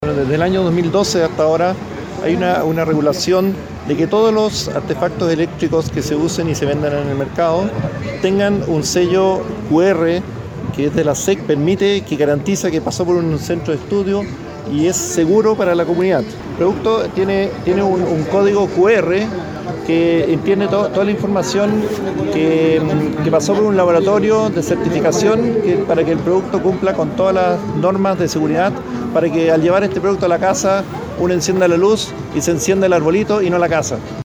El seremi de Energía Tomás Bollinger y el Superintendente de Electricidad y Combustibles – SEC -, Álvaro Loma-Osorio encabezaron la inspección, señalando que las personas deben tener el cuidado de elegir juegos de luces que estén certificados, según lo planteó el seremi de Energía.